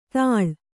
♪ taḷa